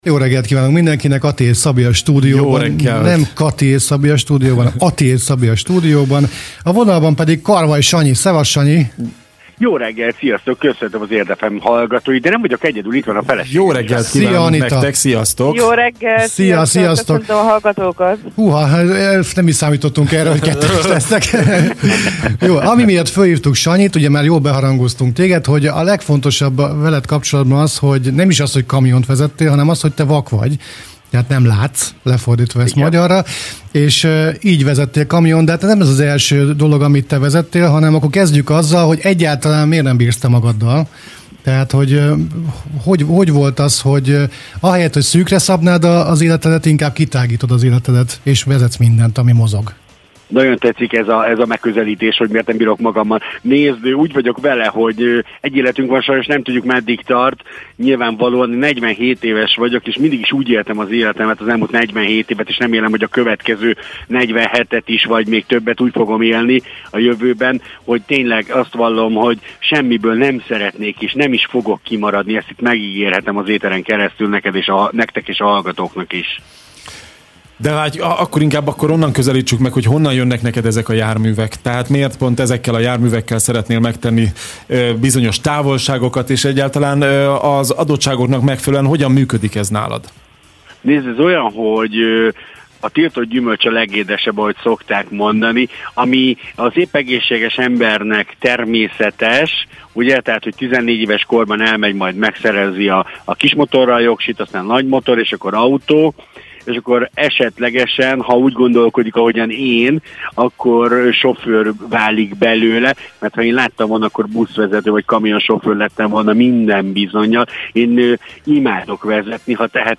teljes interjút itt tudja meghallgatni